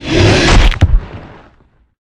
heavy_hit_1.ogg